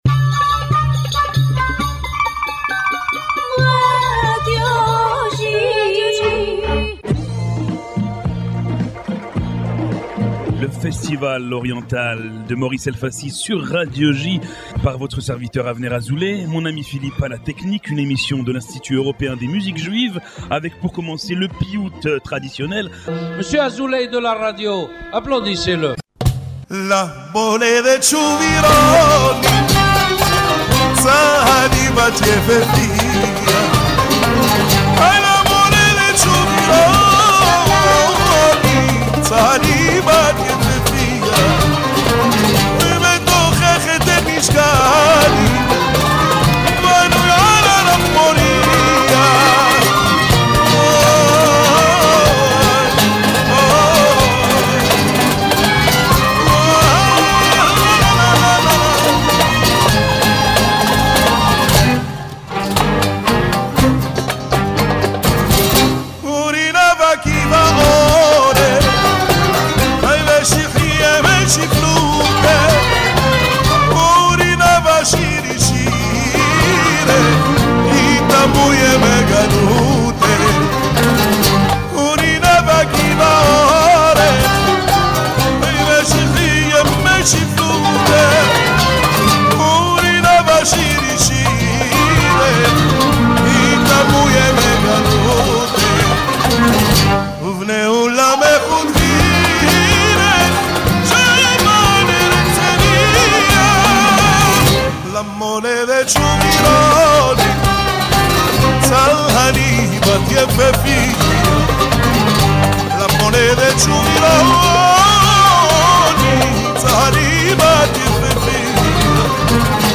Broadcasted every Monday on Radio J (94.8 FM), « The Oriental festival » is a radio program from the European Institute of Jewish Music dedicated to Oriental Music.